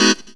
HOUSE 2-R.wav